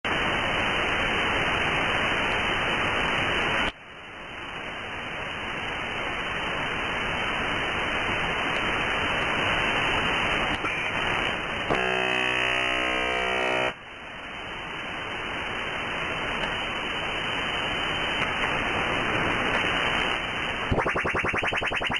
Вы можете прослушать и скачать записи работы станции: гул генераторов, щелчки антенн и другие технические шумы.
Звук радиолокационной станции Дуга в Чернобыле (русский дятел), запись 2 ноября 1984 года